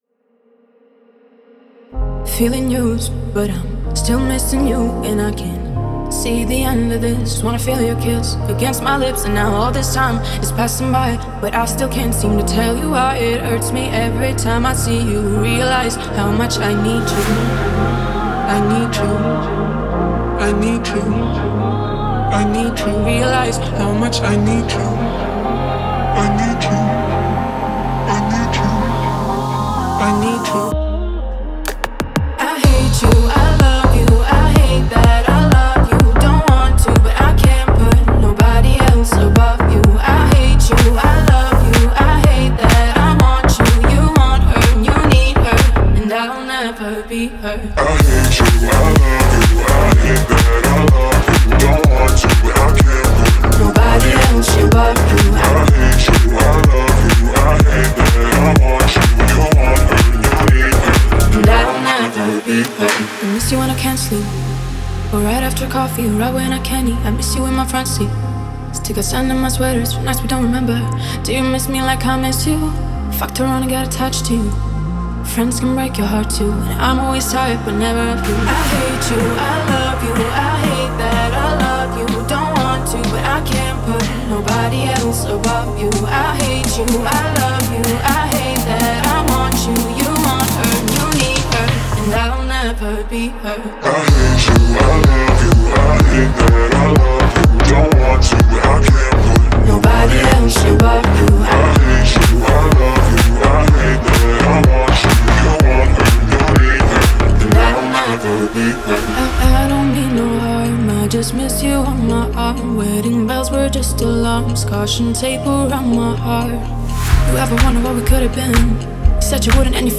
эмоциональный трек в жанре поп с элементами R&B